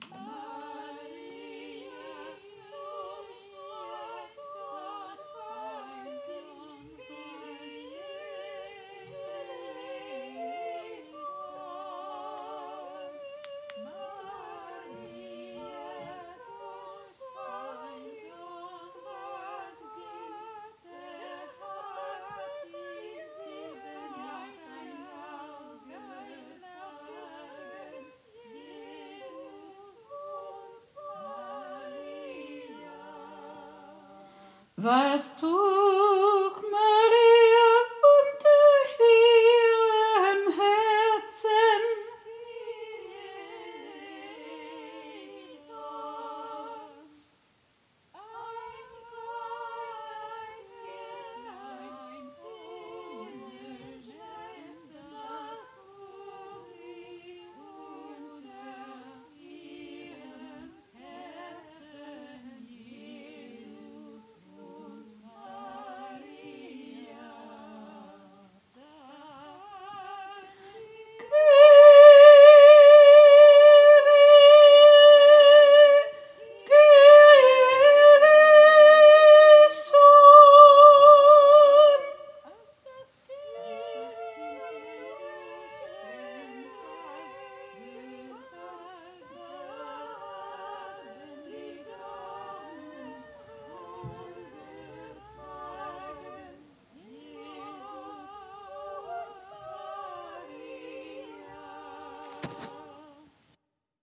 MP3 versions chantées
Solo